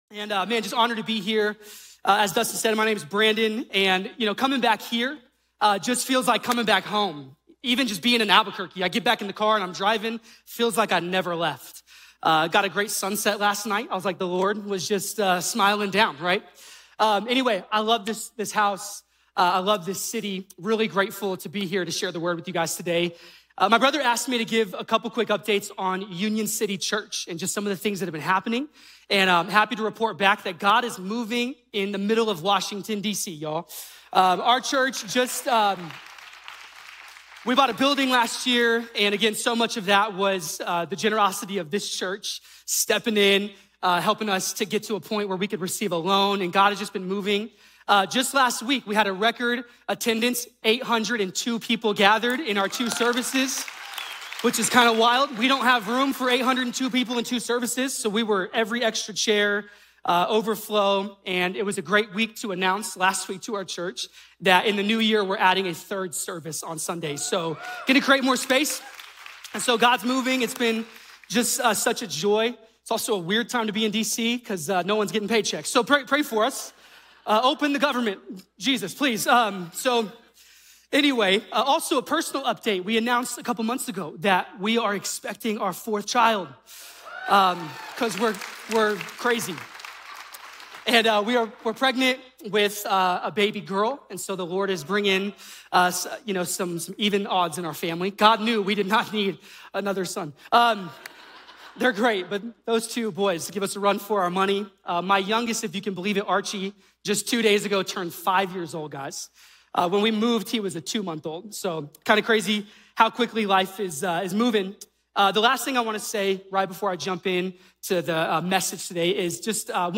A message from the series "The Life of David."